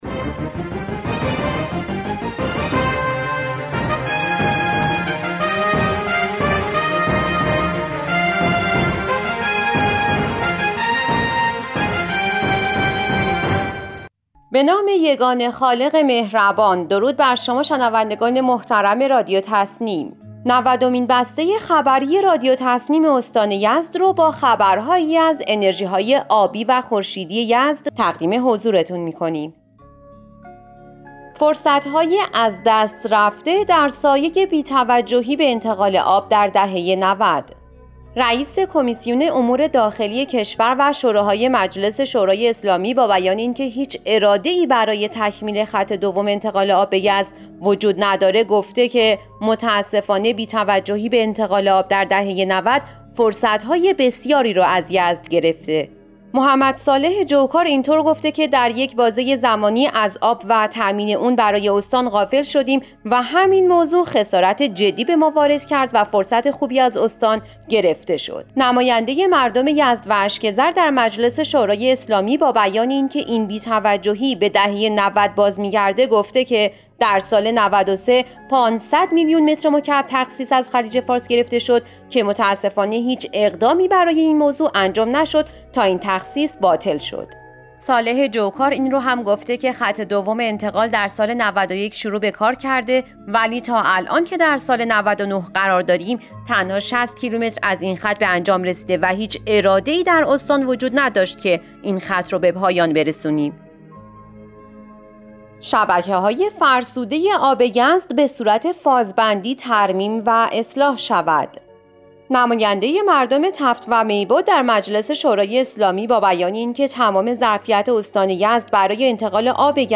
به گزارش خبرگزاری تسنیم از یزد, نودمین بسته خبری رادیو تسنیم استان یزد با خبرهایی از صبحت‌های نمایندگان یزد, میبد و اردکان در مورد استفاده از انرژی‌های آبی و خورشیدی استان منتشر شد.